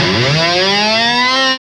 GTR ASCEN0RR.wav